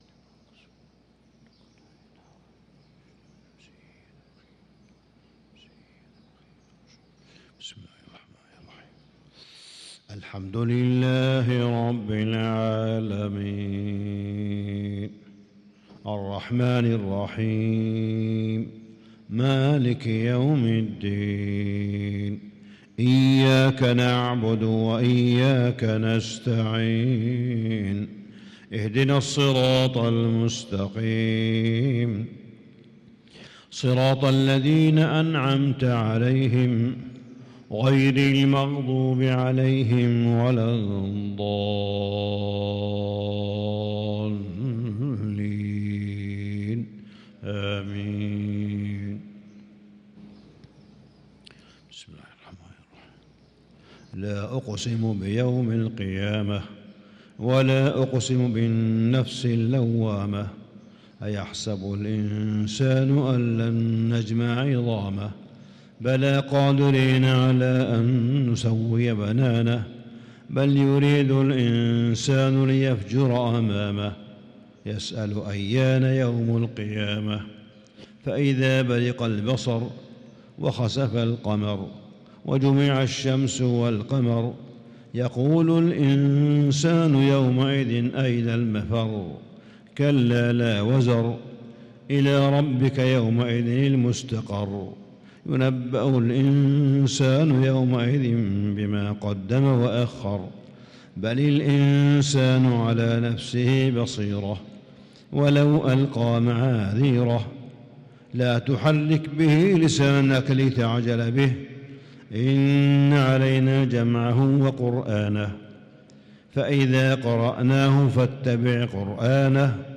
صلاة الفجر للقارئ صالح بن حميد 24 رمضان 1445 هـ
تِلَاوَات الْحَرَمَيْن .